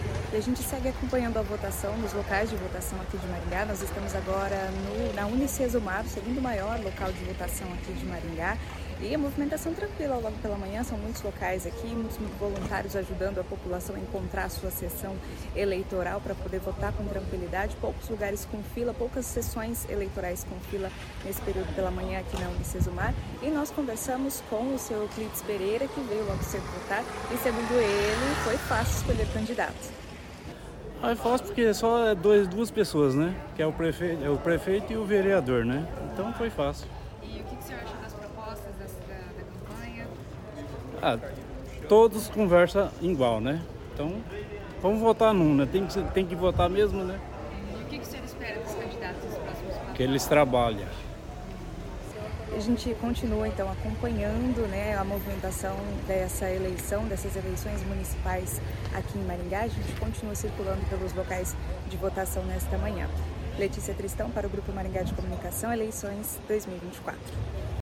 A UniCesumar é o segundo maior local de votação em Maringá. Pela manhã, a movimentação de eleitores na instituição era tranquila.